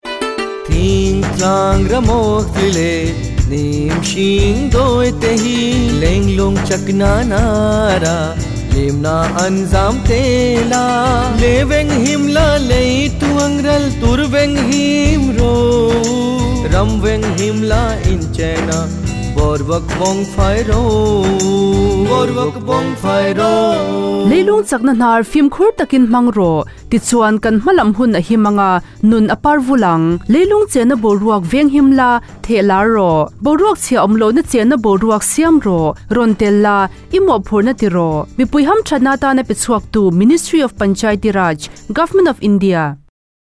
163 Fundamental Duty 7th Fundamental Duty Preserve natural environment Radio Jingle Mizo